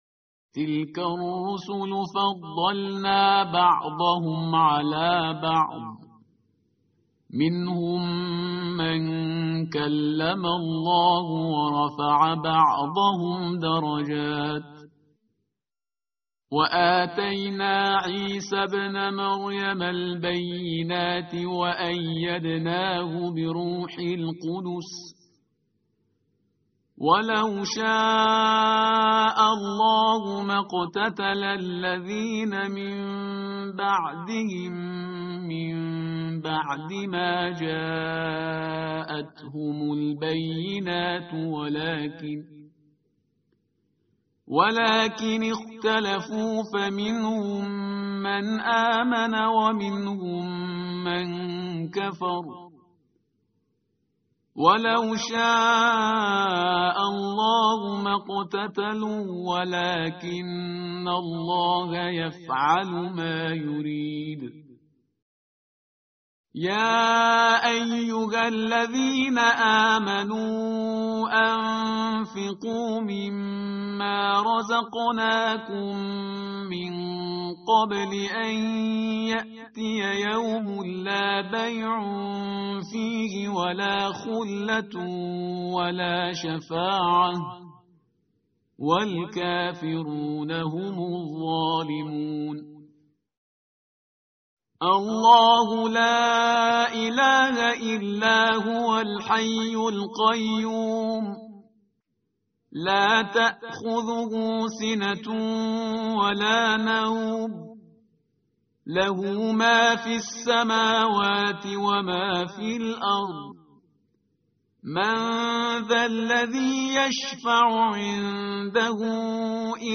tartil_parhizgar_page_042.mp3